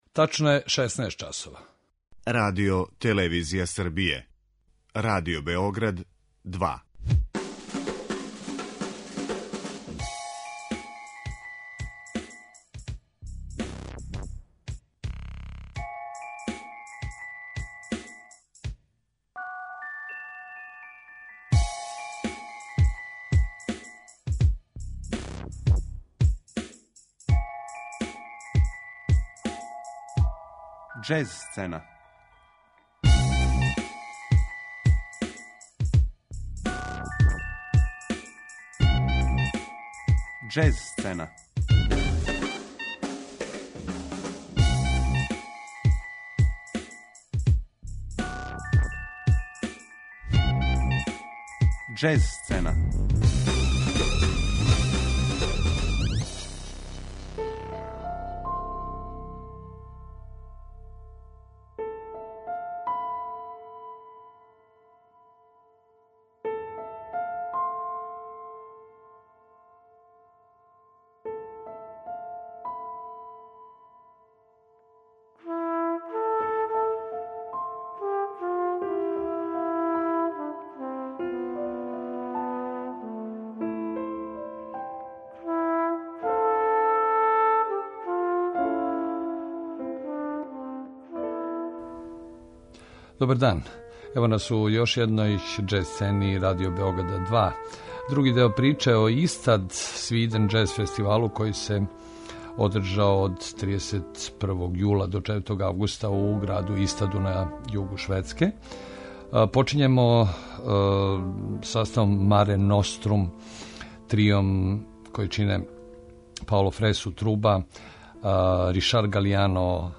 У новом издању Џез сцене Радио Београда 2 дајемо други део осврта на десето јубиларно издање Ystad Sweden Jazz Festivala који је од 31. јула до 4. августа одржан у шведском граду Истаду. Ово је један од уметнички најквалитетнијих фестивала средњег обима у Европи, а у овом осврту слушаћемо музику Бенија Голсона, Сесилије Норби, Омара Сосе и Чарлса Лојда, који су били међу учесницима овогодишње манифестације.